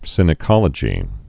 (sĭnĭ-kŏlə-jē)